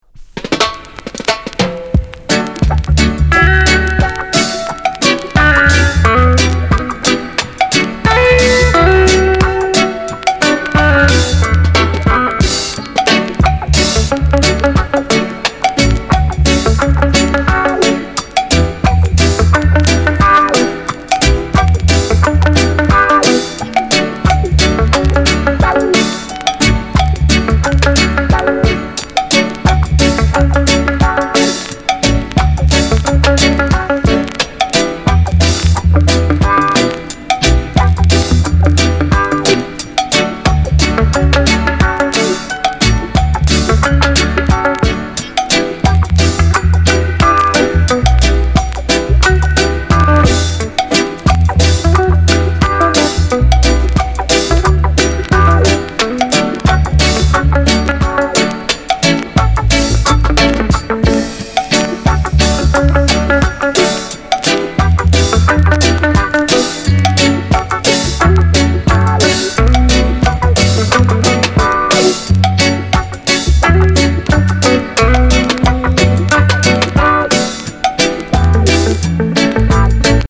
Genre: Reggae/Lovers Rock